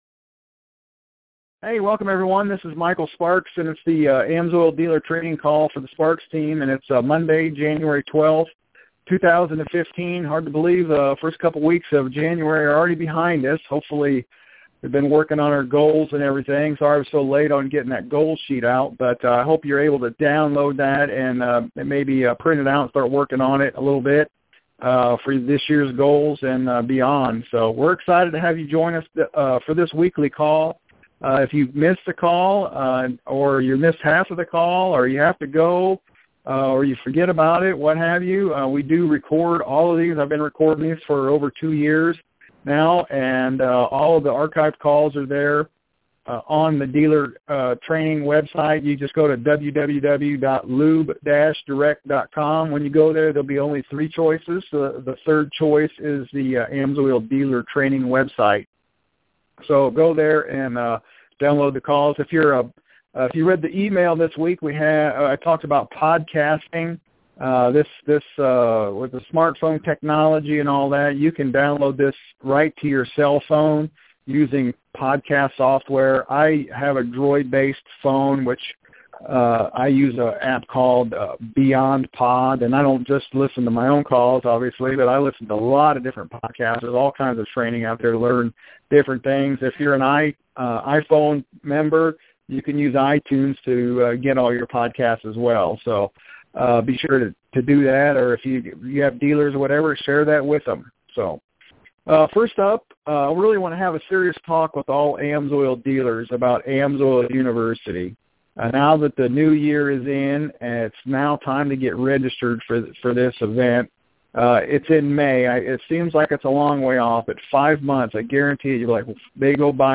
Some great ideas in our weekly AMSOIL Dealer training call to help you start or restart your business.